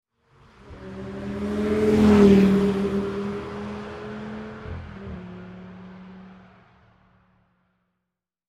Звуки разгона машины
Звук проезжающего слева направо спортивного автомобиля в стерео